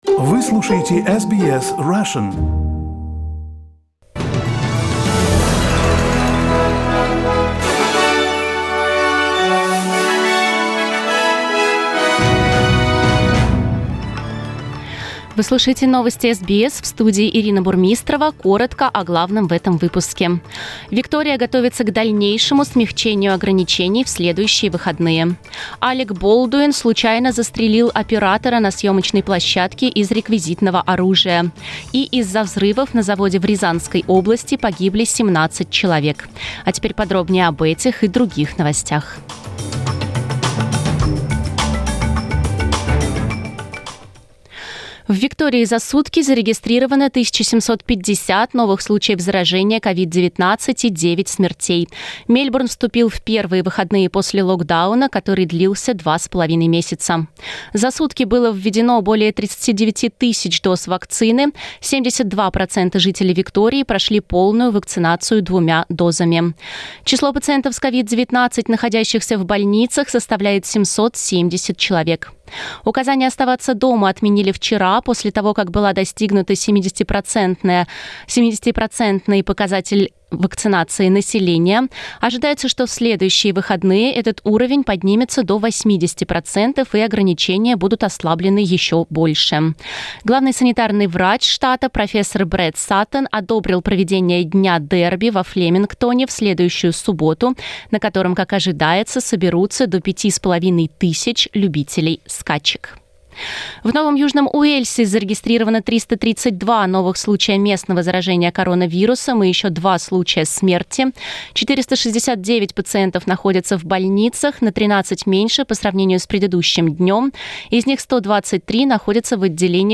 Новости SBS на русском языке - 23.10